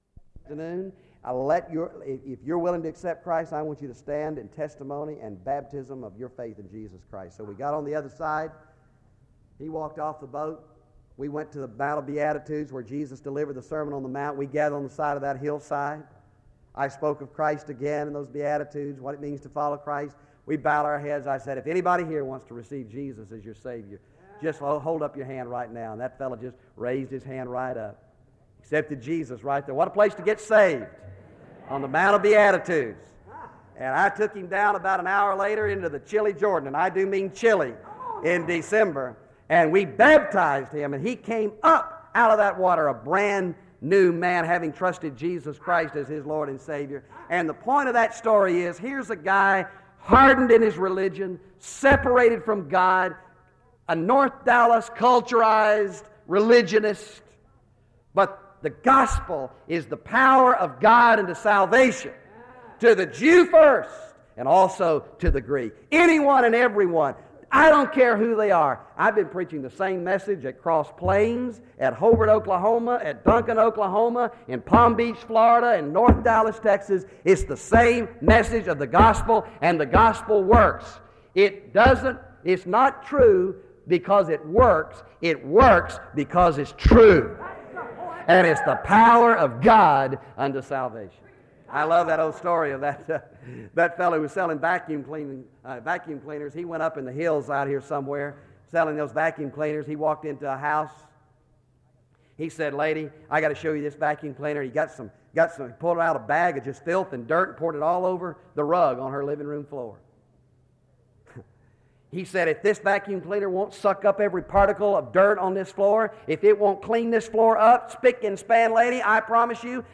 SEBTS Convocation
• Wake Forest (N.C.)